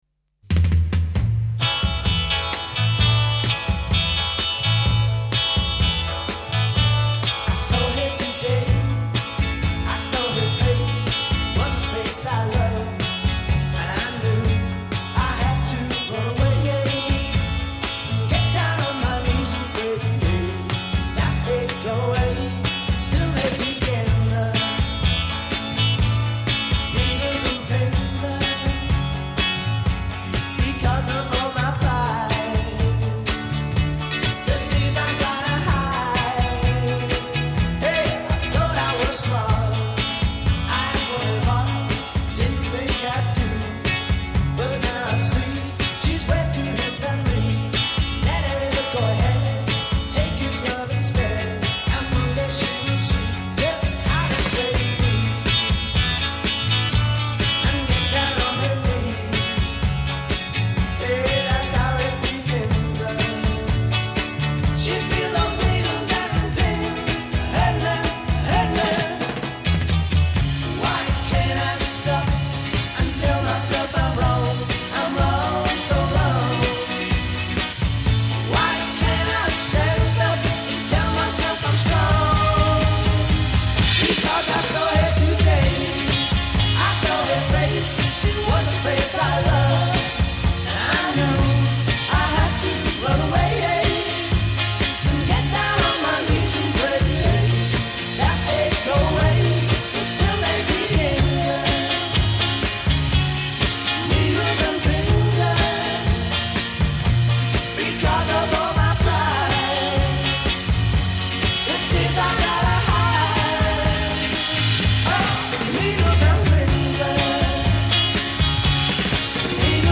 vocals & guitar
vocals and bass guitar
drums and vocals
minor annoyance: squeaky drum pedal)
A verse 0: 8+8 double tracked vocal solo a
B chorus : 8 harmonized; modulation to new key area c
A verse : 8+8 C# major a